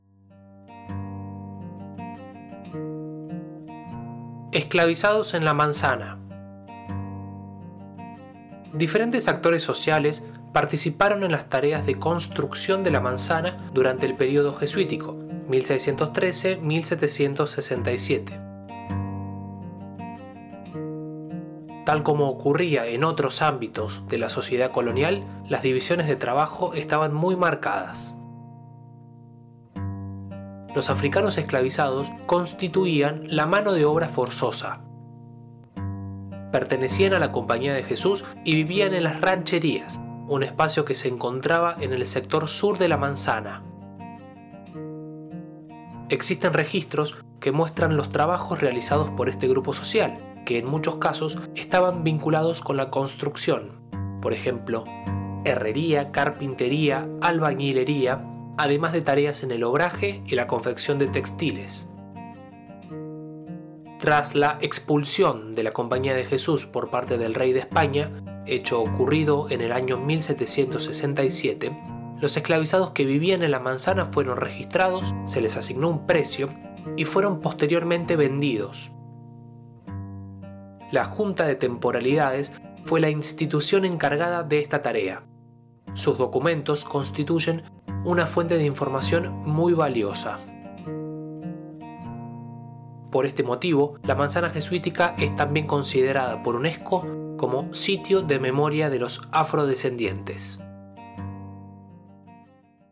[Audioguía]